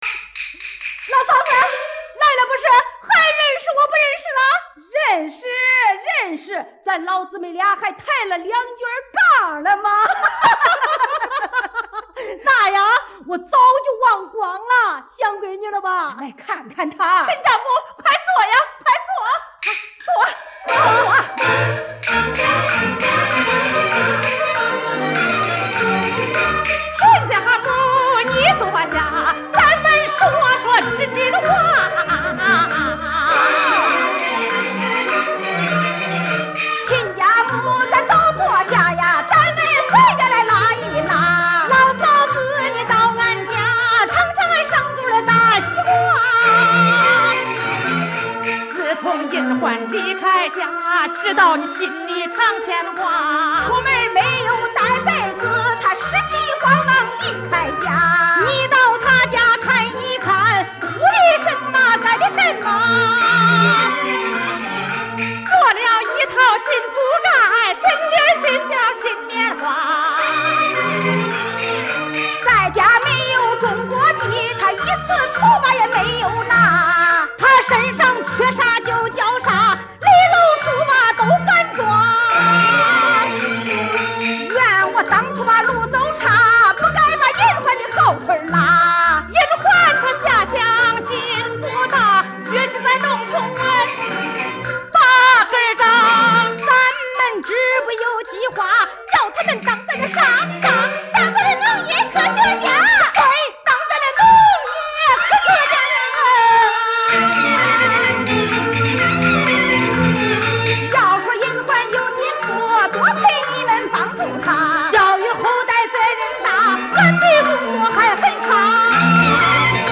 此版本演唱较其它版本更高亢 激情，但缺少一些生活气息。